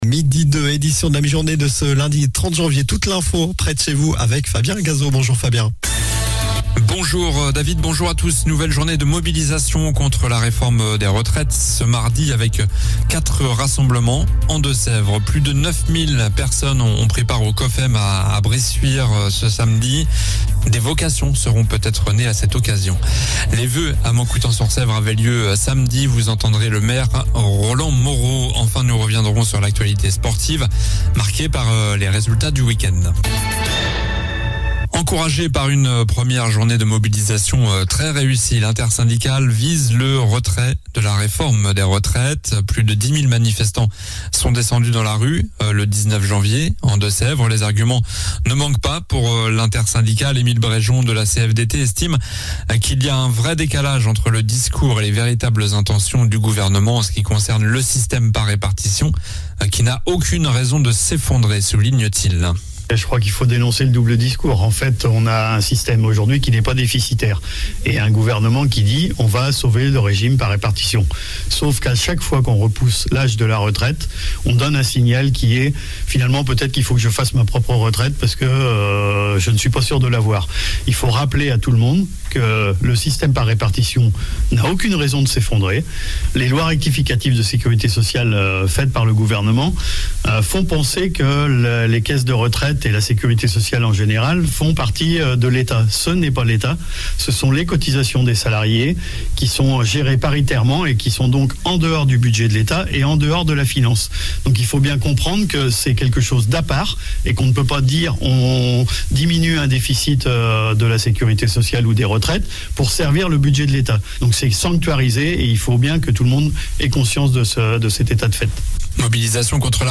Journal du lundi 30 janvier (midi)